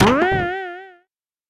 Trampoline sound effect from Super Mario 3D World.
SM3DW_Trampoline_High.oga